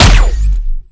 fire_gun6_rank4.wav